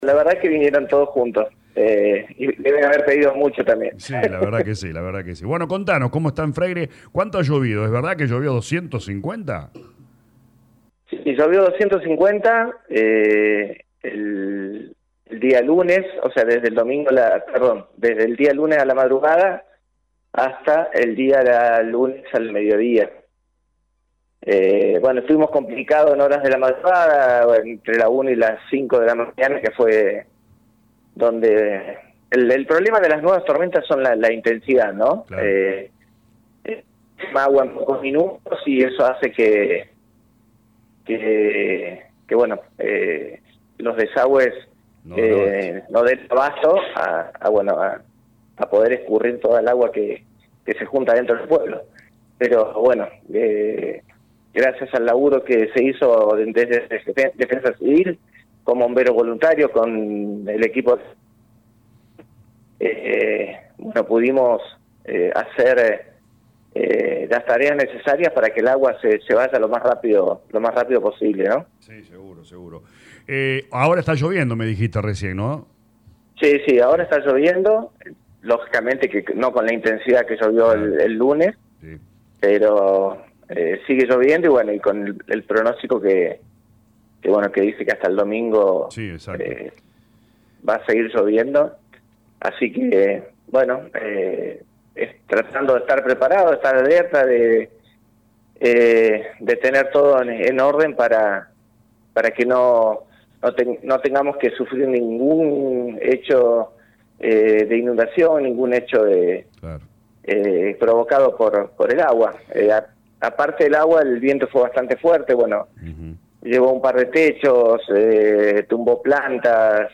Llueve en la mañana de hoy miercoles en Freyre y el registro se acerca a los 260 mm. Habló en LA RADIO 102.9 el intendente Germàn Baldo quien confirmo la postergación del evento.